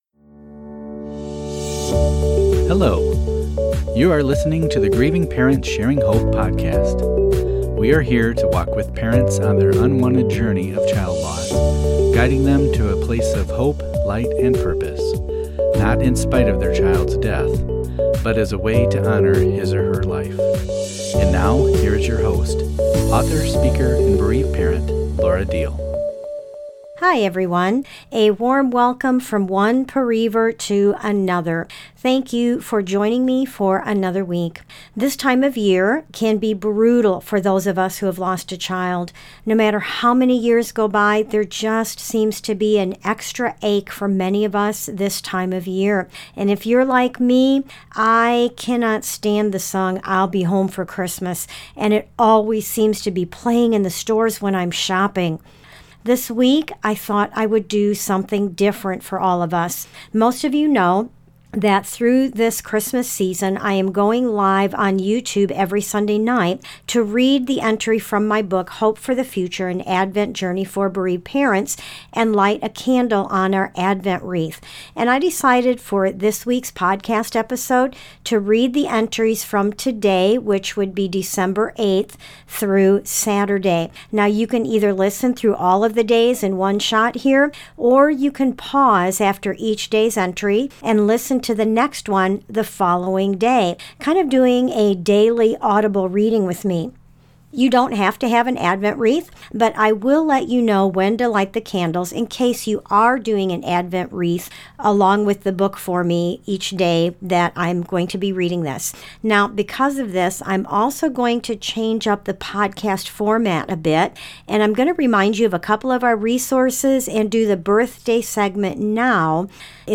she reads several comforting and encouraging daily entries